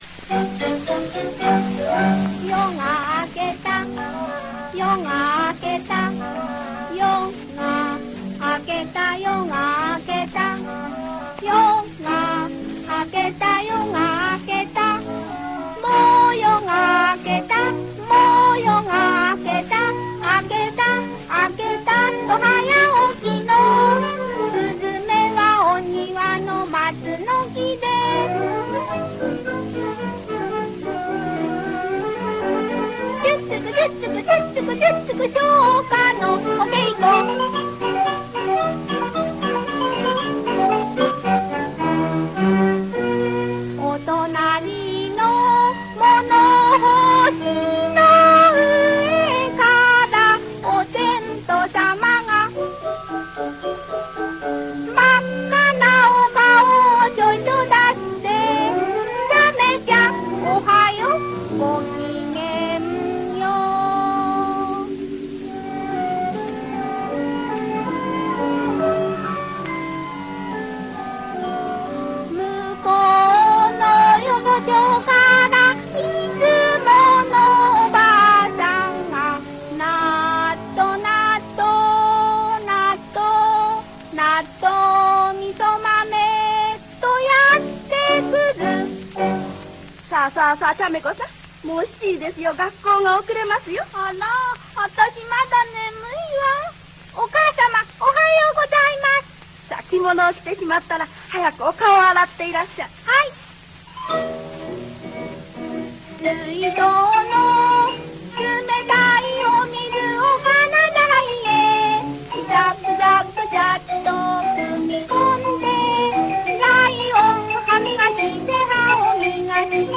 童謡唱歌
独唱